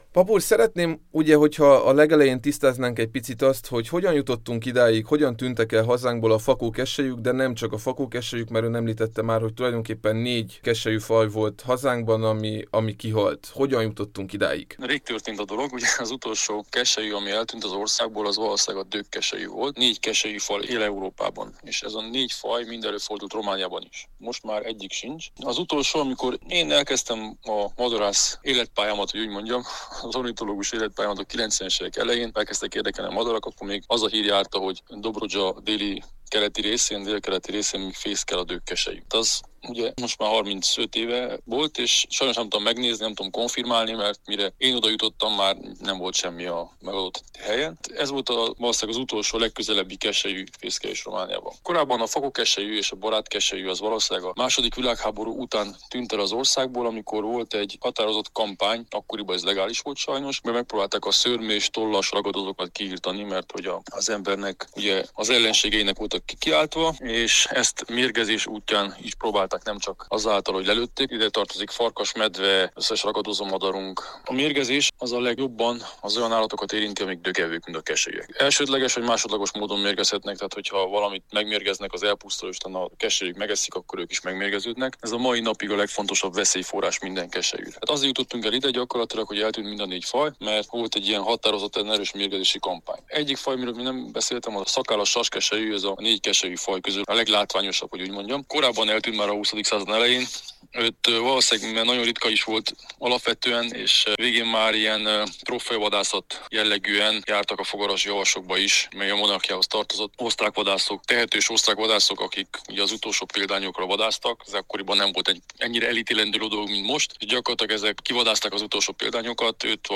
ornitológust